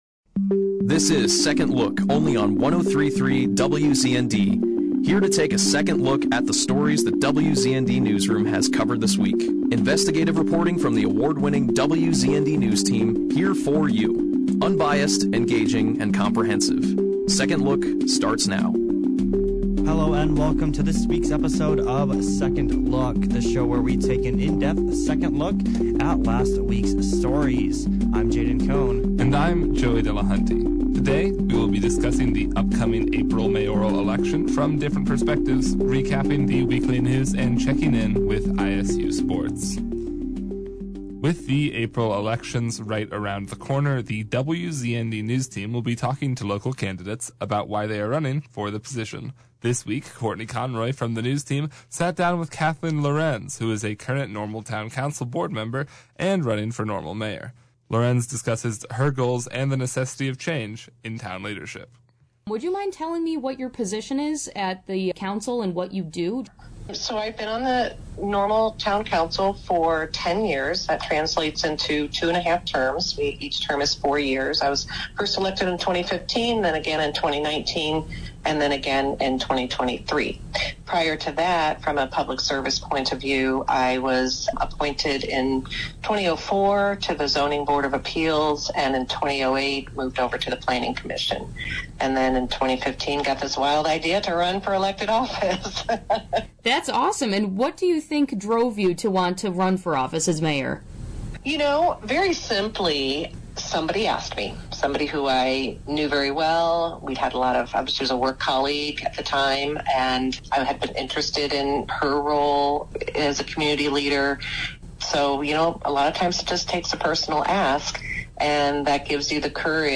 The show opened with an interview